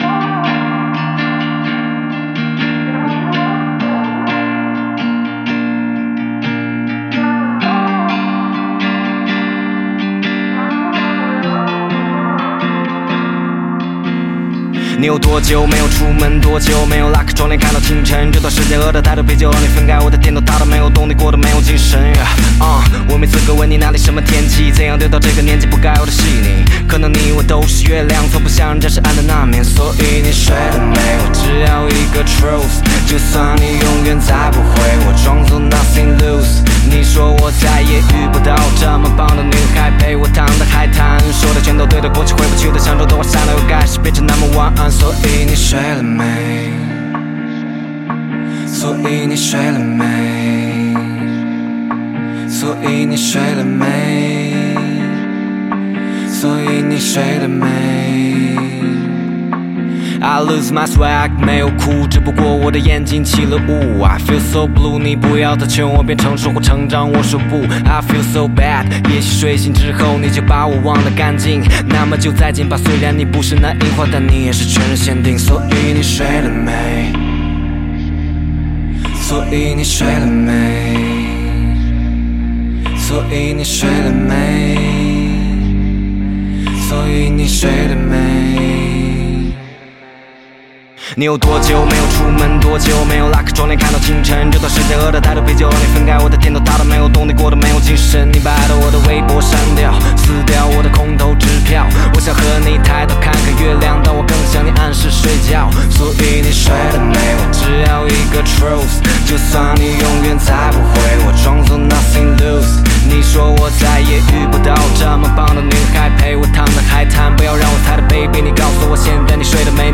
Ps：在线试听为压缩音质节选，体验无损音质请下载完整版
和声